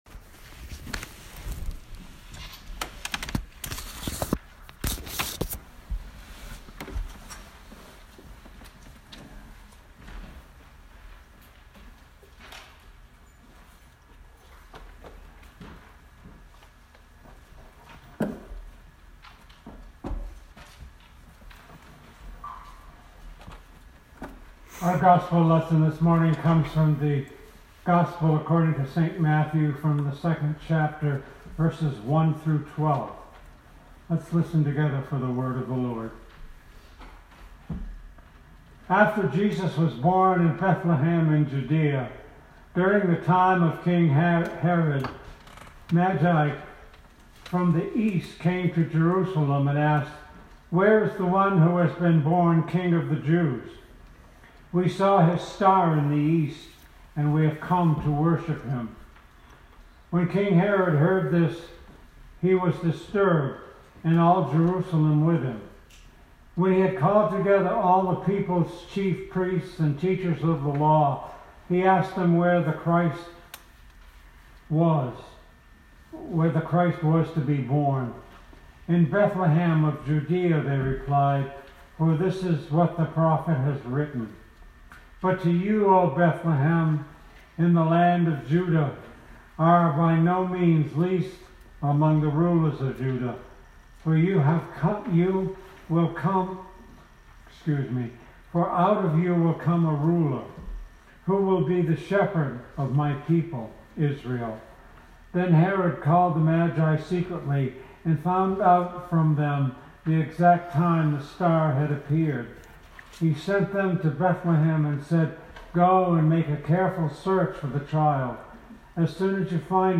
Sermon 2020-01-05